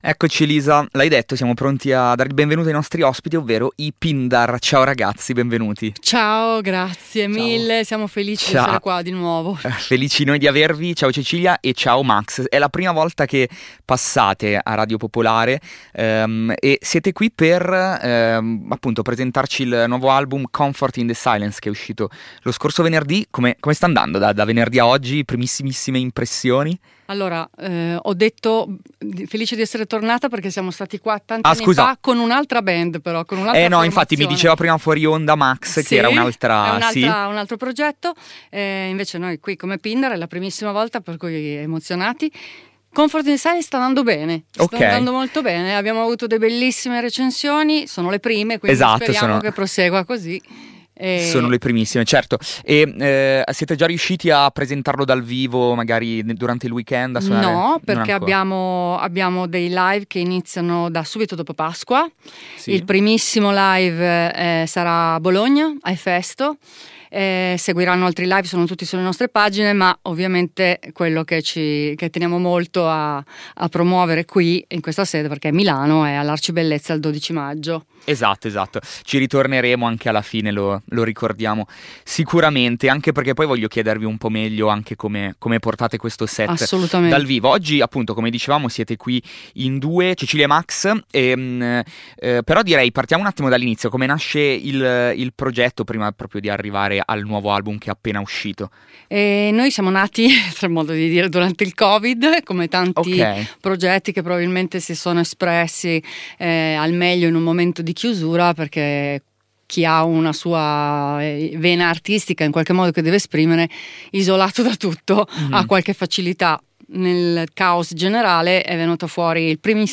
Oggi, la band milanese è passata a trovarci a Volume per presentare il disco e suonarci dal vivo qualcuno dei nuovi pezzi.